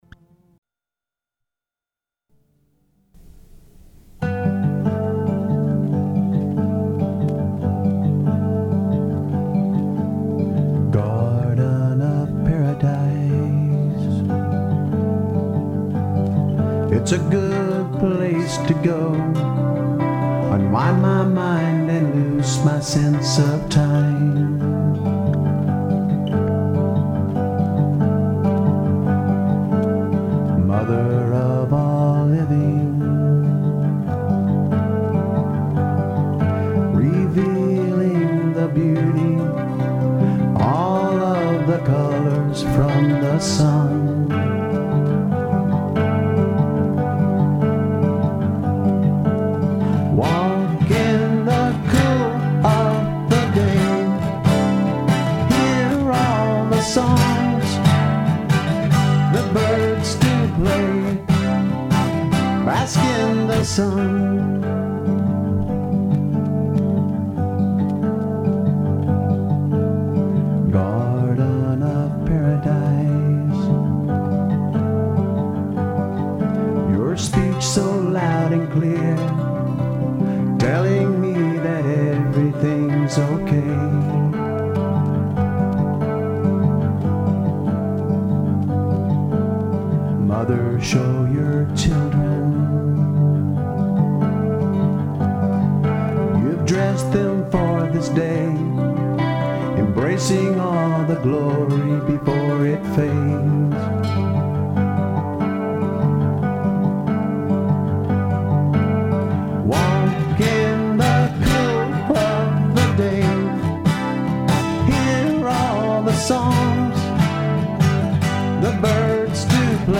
ORIGINAL ACOUSTIC SONGS
BASS
ESPECIALLY AT THE DALLAS ARBORETUM WHERE THEY WERE FEATURED EACH THURSDAY